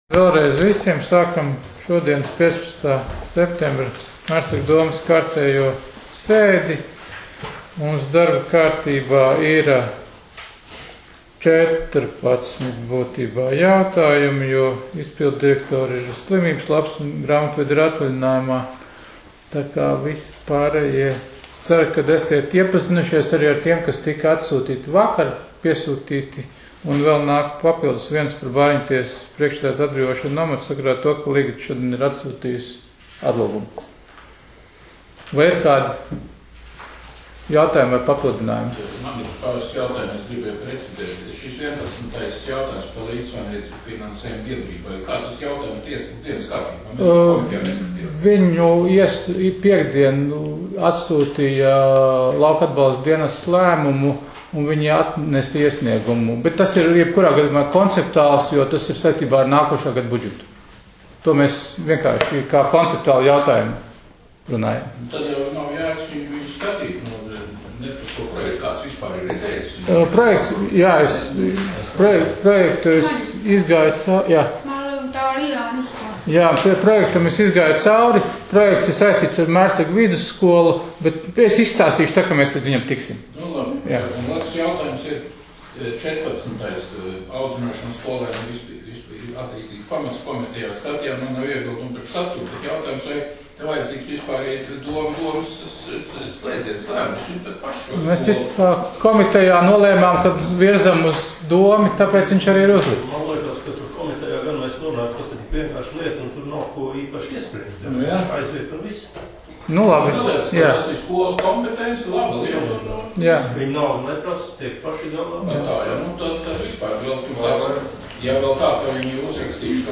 Mērsraga novada domes sēde 15.09.2020.